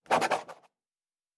Writing 5.wav